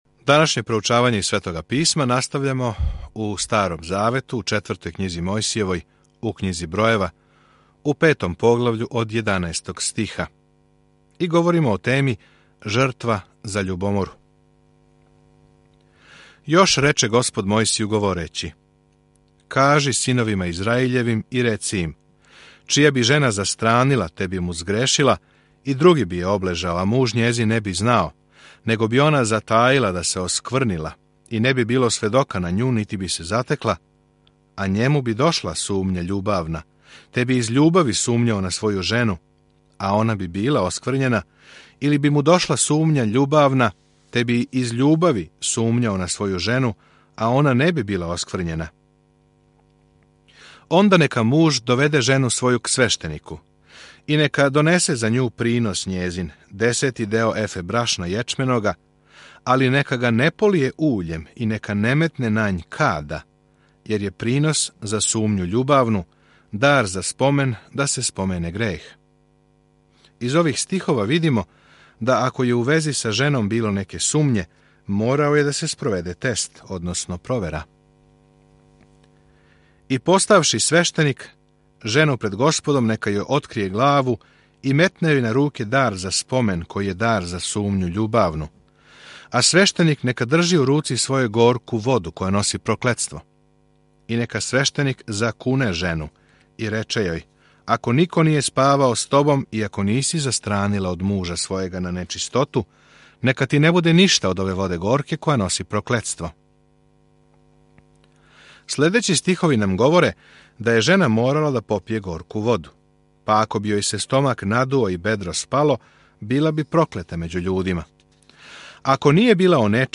Mojsijeva 7:1-18 Dan 3 Započni ovaj plan Dan 5 O ovom planu У Књизи Бројева, ми ходамо, лутамо и обожавамо се са Израелом током 40 година у пустињи. Свакодневно путујте кроз Бројеве док слушате аудио студију и читате одабране стихове из Божје речи.